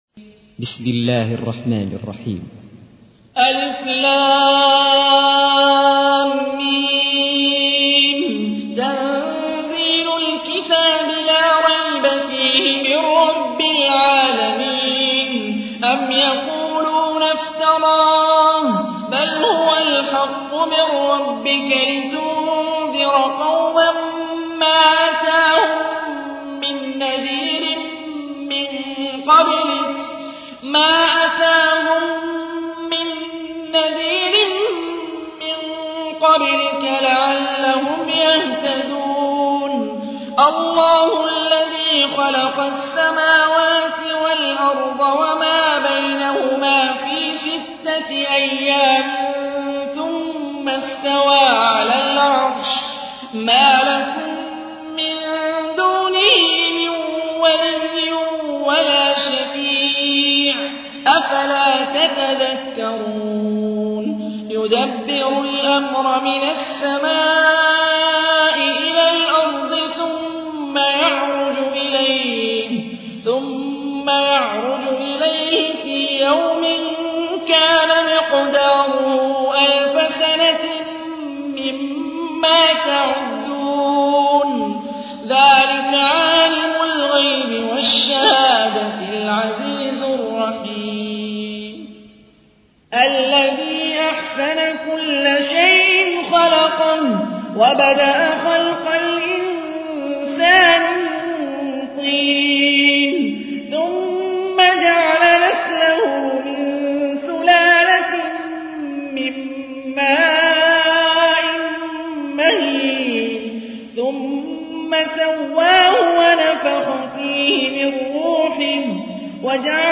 تحميل : 32. سورة السجدة / القارئ هاني الرفاعي / القرآن الكريم / موقع يا حسين